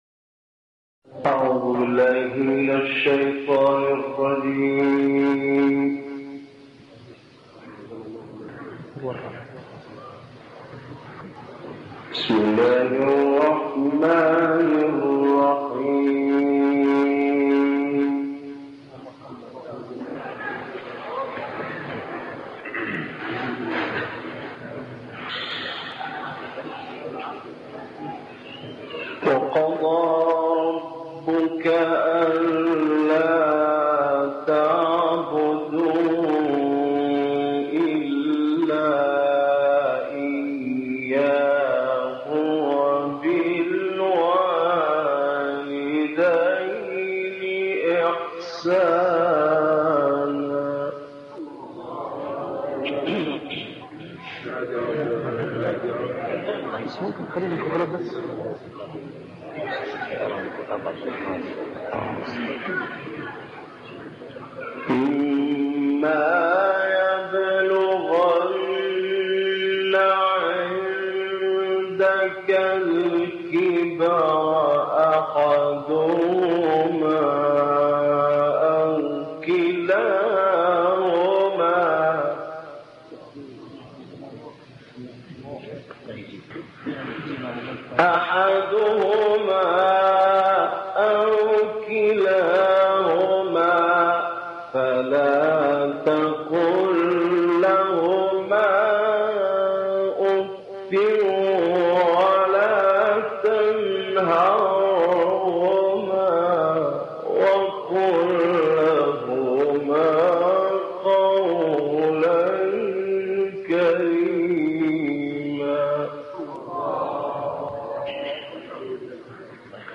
گروه فعالیت‌های قرآنی: شاهکاری از تلاوت استاد محمد اللیثی از سوره بنی‌اسرائیل، آیات ۳۹- ۲۳ ارائه می‌شود.